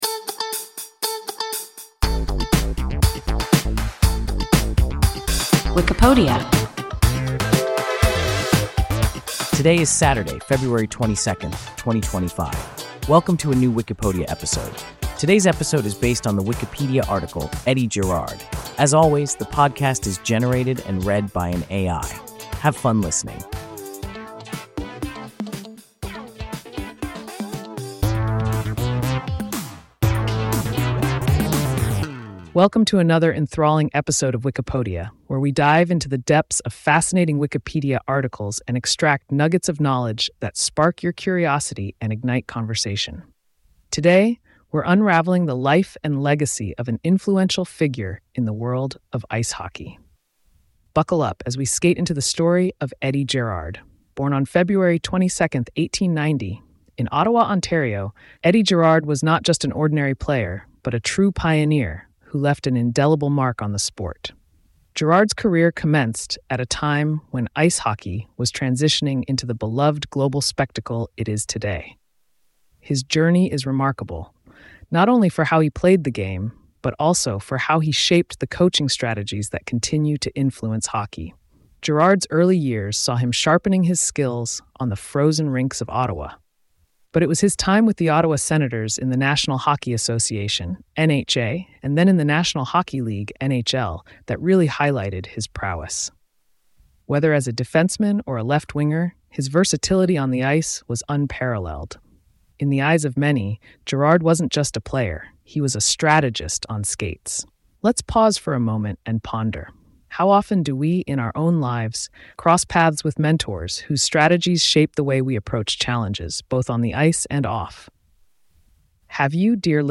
Eddie Gerard – WIKIPODIA – ein KI Podcast